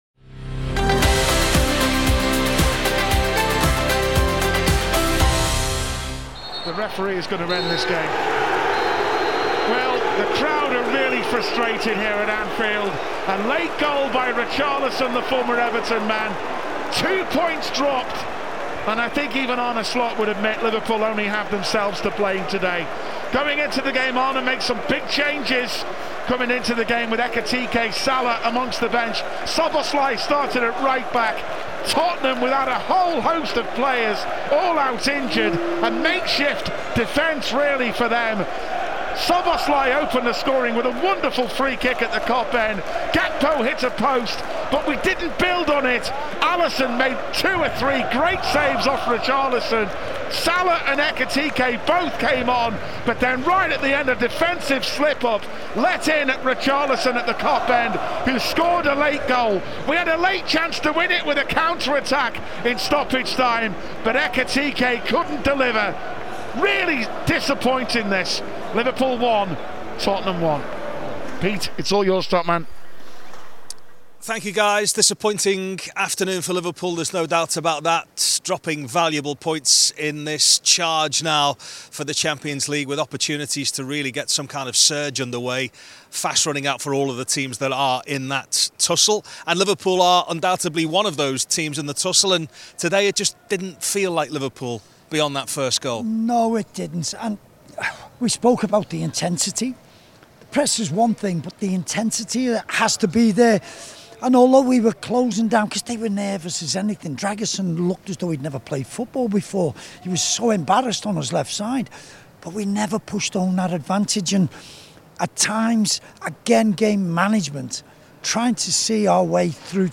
Headliner Embed Embed code See more options Share Facebook X Subscribe Arne Slot was left frustrated as Liverpool and Tottenham Hotspur played out a 1-1 draw at Anfield in the Premier League on Sunday afternoon. In this episode of The Reaction we'll hear from the boss, as well as former players Phil Thompson and Martin Kelly,